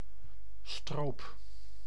Ääntäminen
IPA : /ˈsɪɹəp/